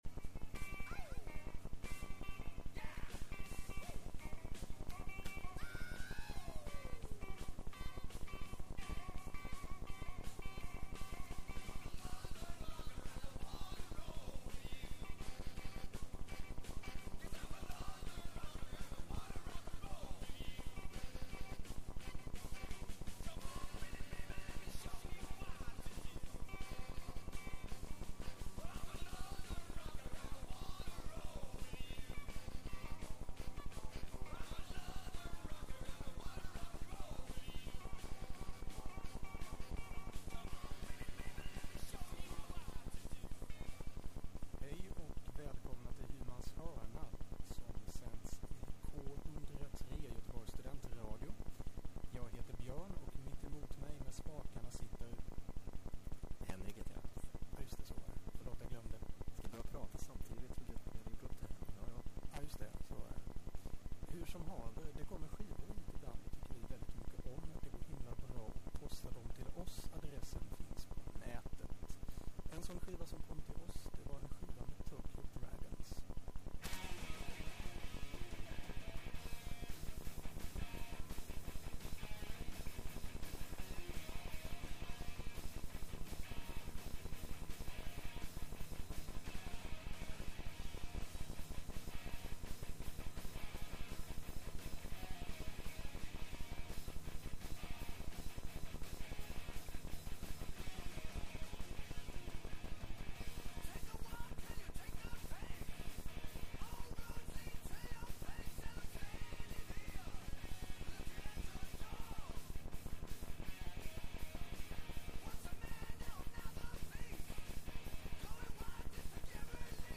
Det blir nya och gamla norska band, vissa har splittrats, vissa har återförenats men alla är de från grannlandet i väst.